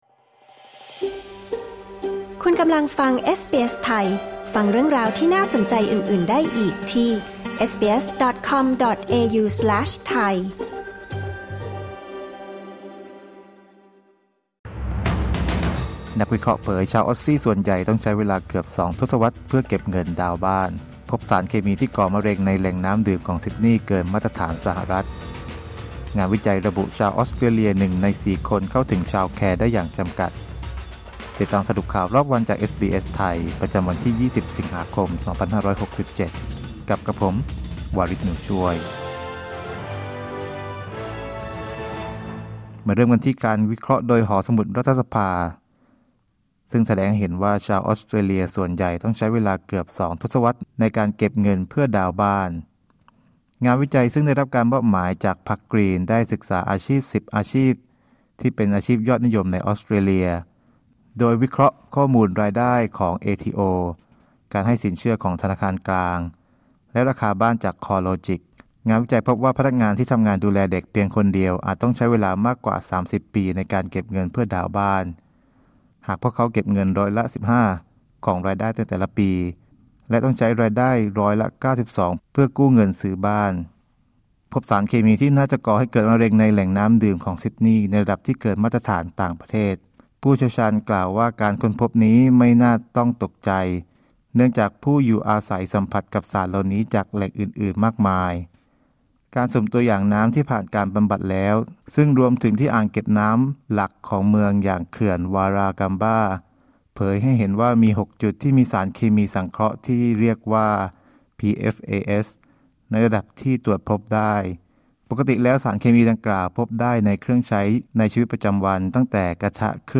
สรุปข่าวรอบวัน 20 สิงหาคม 2567